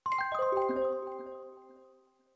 Old Music Box 2
bonus-sound film-production game-development intro magic music-box mystic mystical sound effect free sound royalty free Music